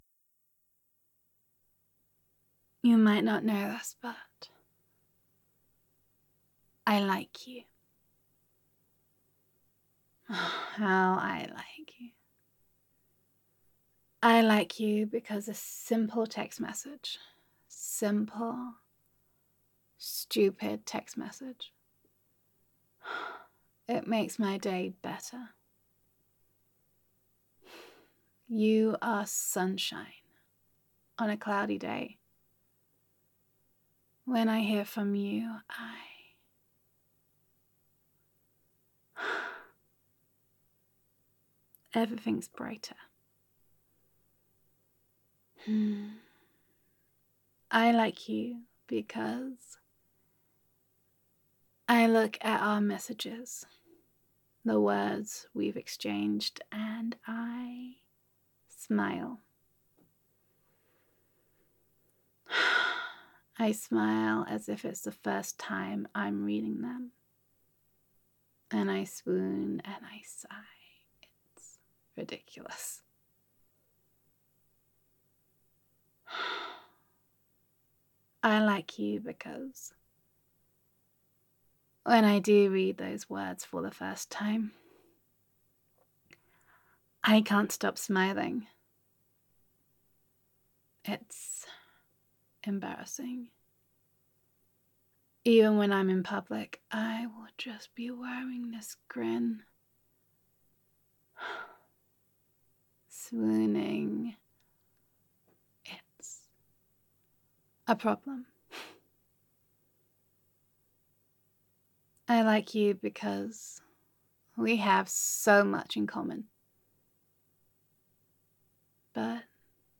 [F4A] Why I like You [Utterly Besotted][Warm and Sweet][Gender Neutral][Hopelessly Smitten with You]